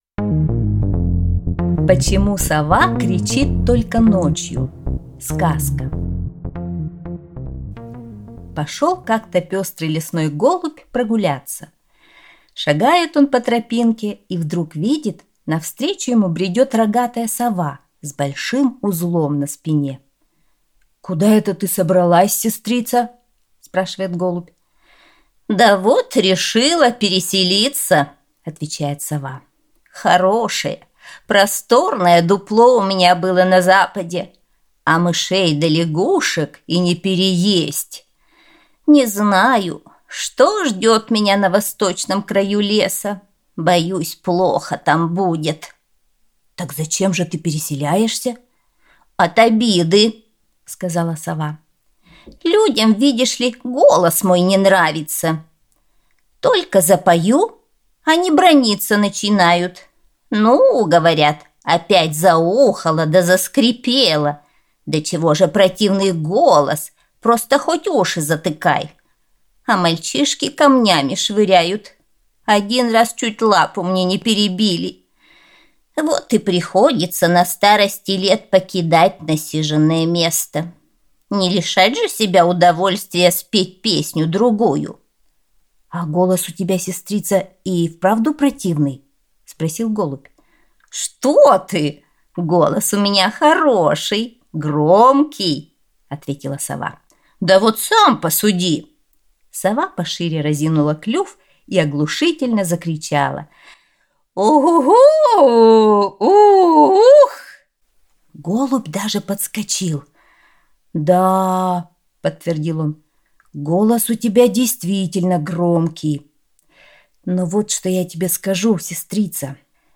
Почему сова кричит только ночью – китайская аудиосказка